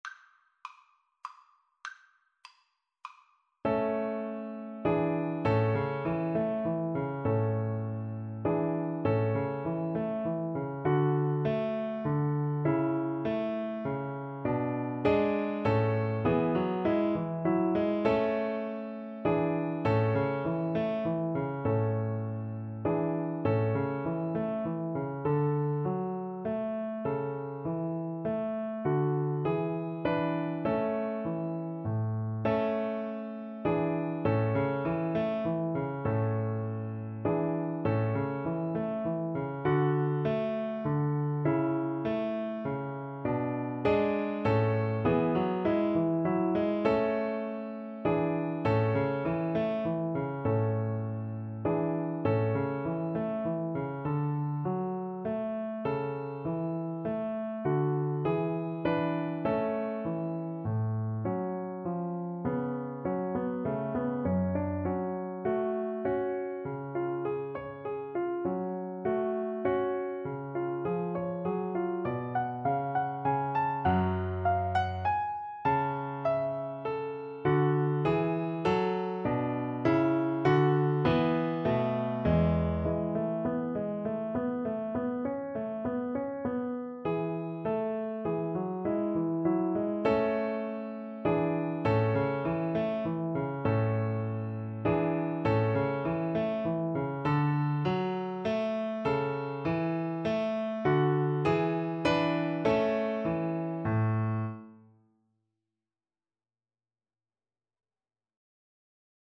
• Unlimited playalong tracks
Moderato
3/4 (View more 3/4 Music)
Classical (View more Classical Violin Music)